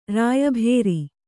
♪ rāya bhēri